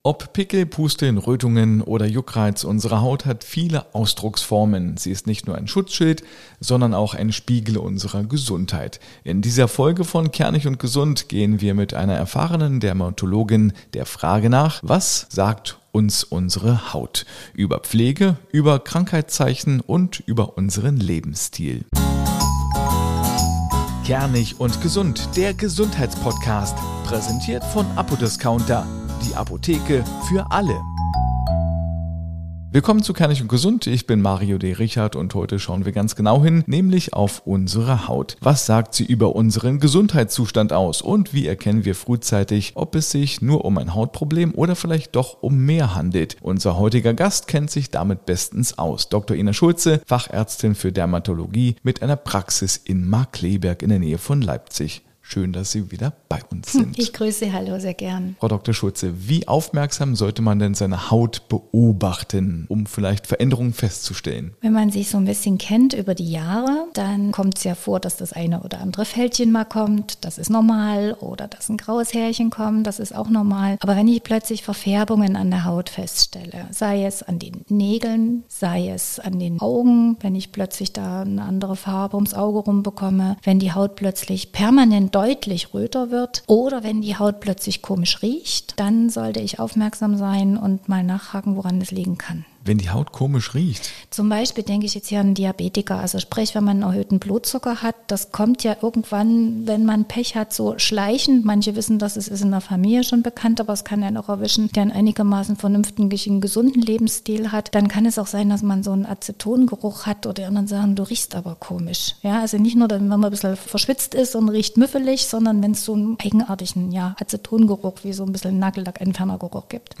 Beschreibung vor 5 Monaten Ob Pickel, Pusteln, Rötungen oder Juckreiz – unsere Haut hat viele Ausdrucksformen. Sie ist nicht nur ein Schutzschild, sondern auch ein Spiegel unserer Gesundheit. In dieser Folge von „kernig & gesund“ gehen wir mit einer erfahrenen Dermatologin der Frage nach: Was sagt uns unsere Haut über unseren Gesundheitszustand?